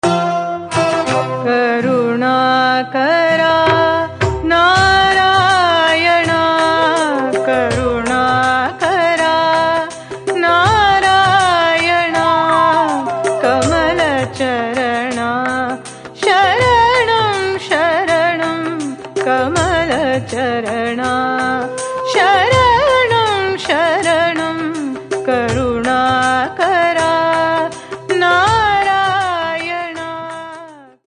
Devotional Bhajans